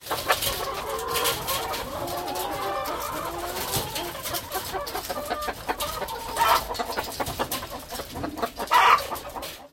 На этой странице собраны разнообразные звуки курятника: от кудахтанья кур до петушиных криков на рассвете.
Шум кур в курятнике